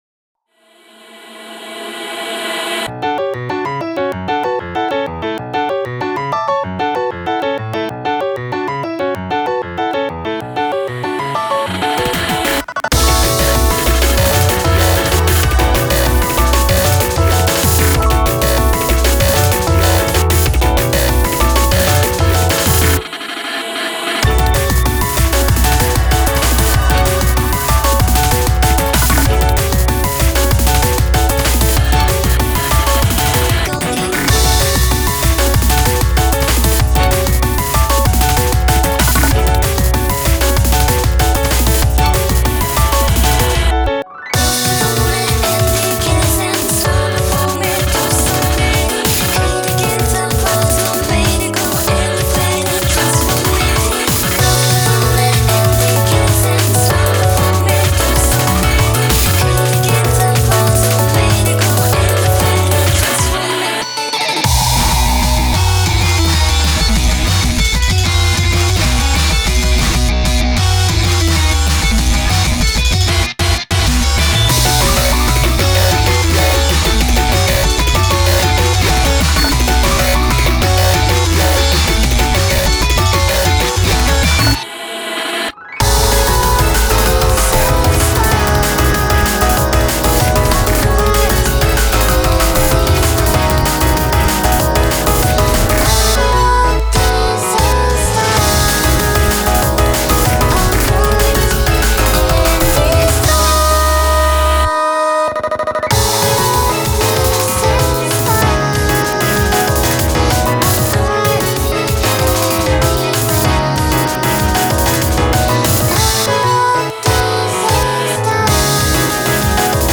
BPM191
Audio QualityPerfect (High Quality)
Genre: fake out.